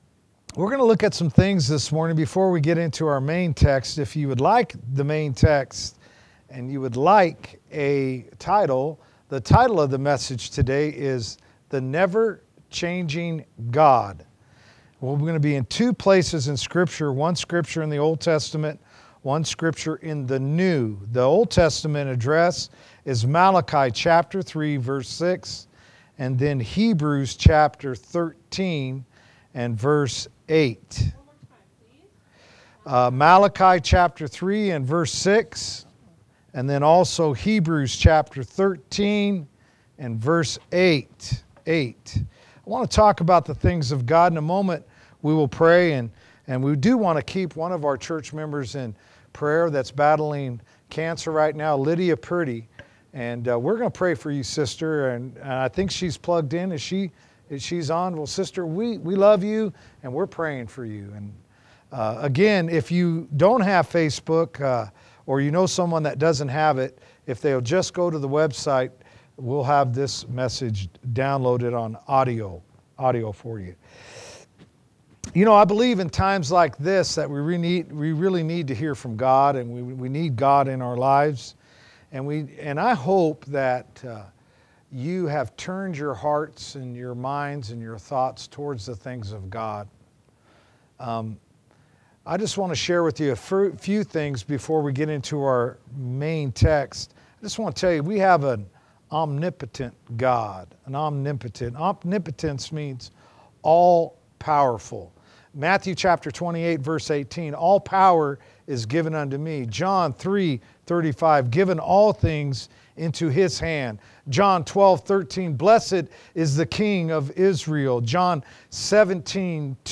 A message from the series "Out of Series."